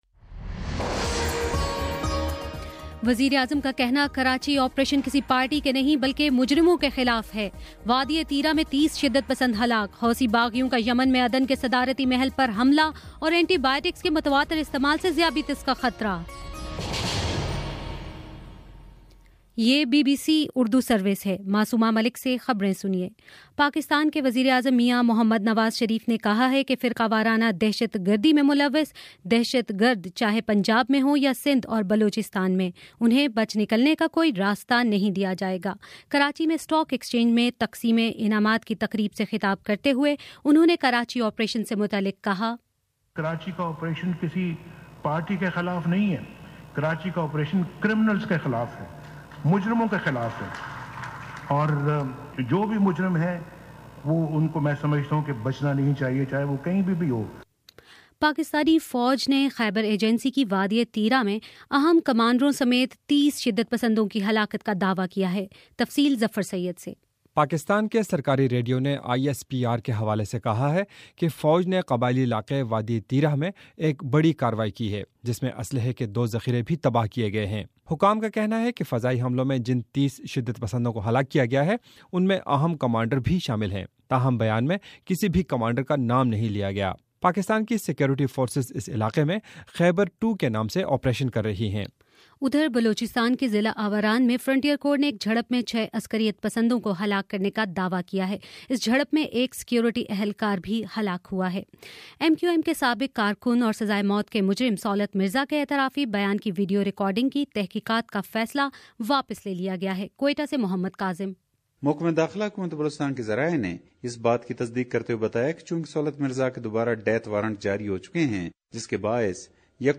مارچ25: شام سات بجے کا نیوز بُلیٹن